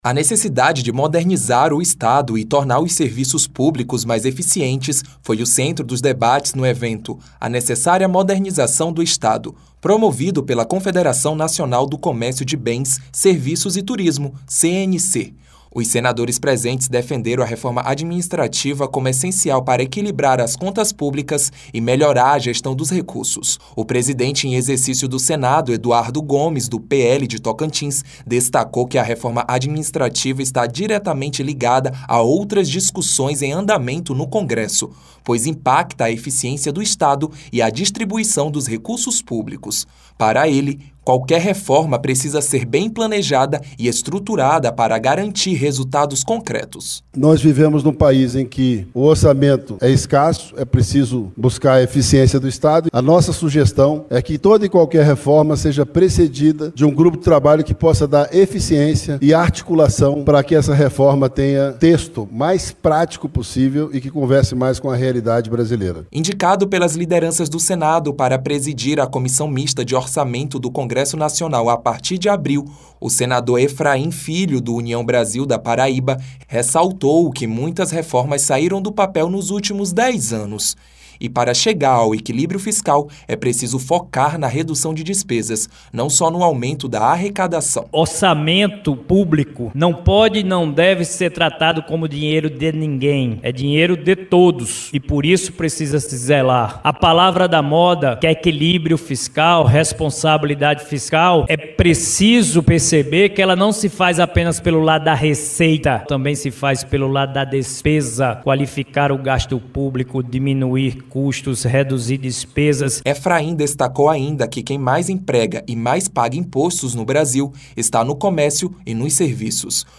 A reforma administrativa foi debatida nesta terça-feira (25) durante o evento “A Necessária Modernização do Estado”, promovido pela Confederação Nacional do Comércio de Bens, Serviços e Turismo (CNC), em Brasília. O senador Efraim Filho (União Brasil-PB), indicado para presidir a Comissão Mista de Orçamento (CMO) a partir de abril, defendeu a necessidade de qualificar os gastos públicos para alcançar o equilíbrio fiscal.